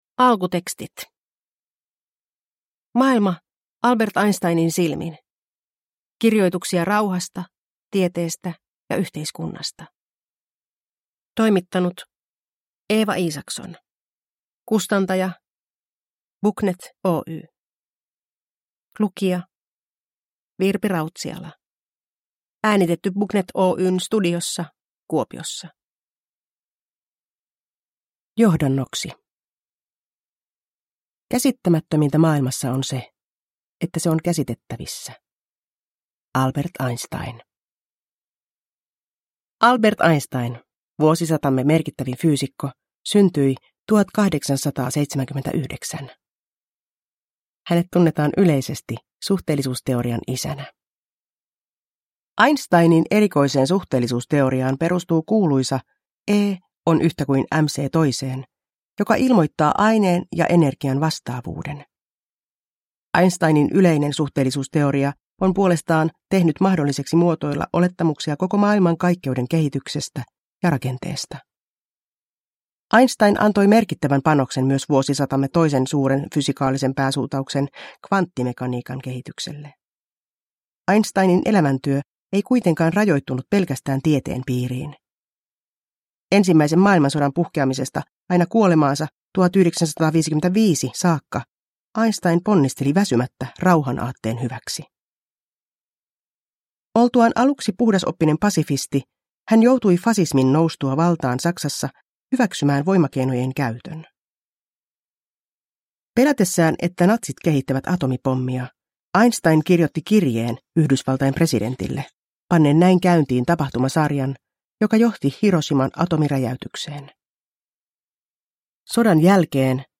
Nedladdningsbar Ljudbok
Ljudbok
Berättare